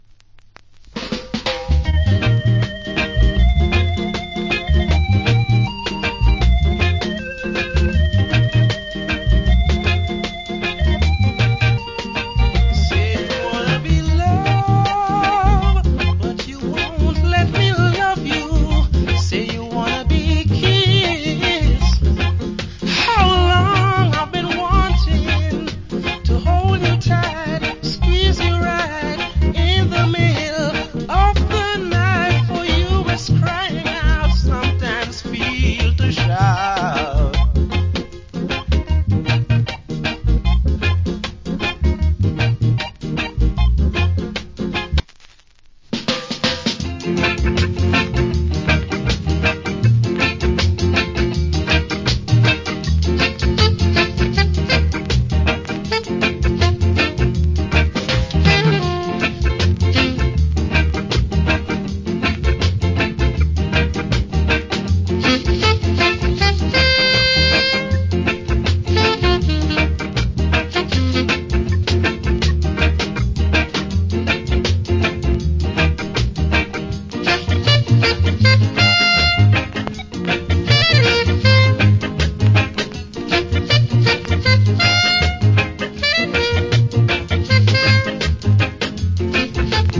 Wicked Early Reggae Vocal. / Nice Early Reggae Inst.